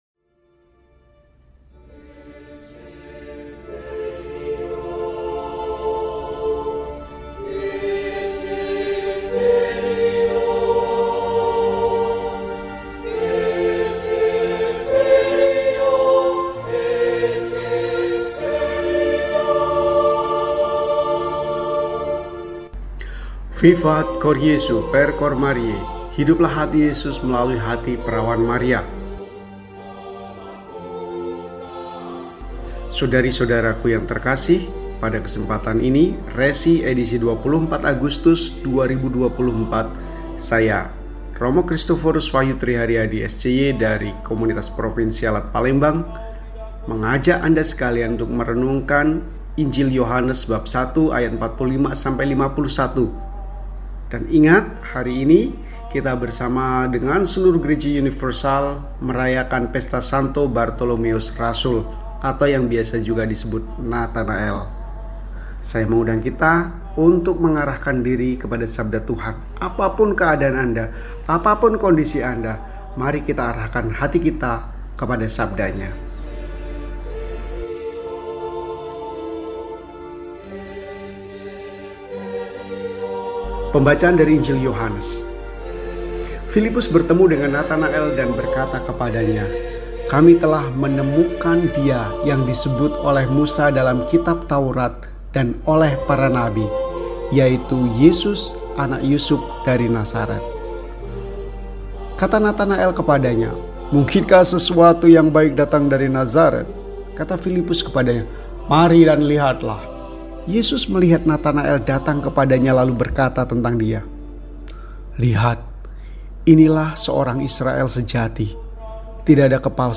Sabtu, 25 Agustus 2024 – Pesta St. Bartolomeus, Rasul – RESI (Renungan Singkat) DEHONIAN